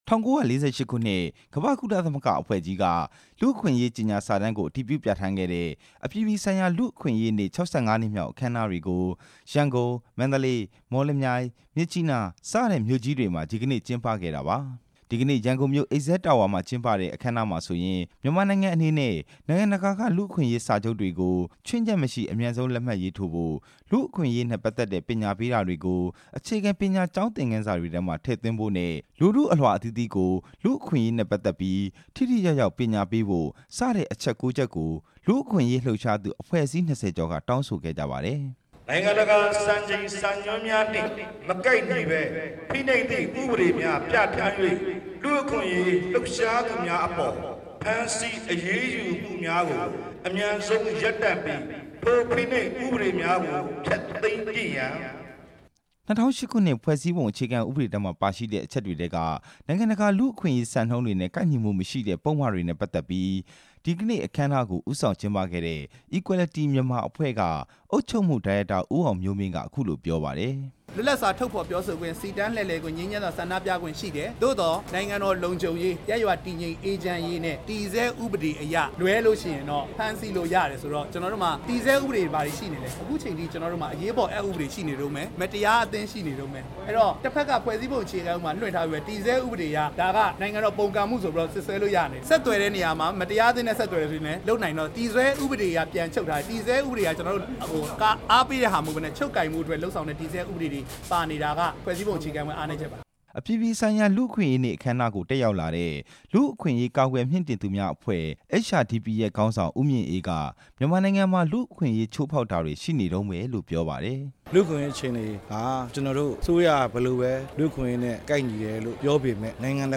လူ့အခွင့်အရေးနေ့ အခမ်းအနားတွေအကြောင်း တင်ပြချက်